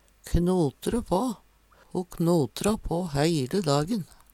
knote på - Numedalsmål (en-US)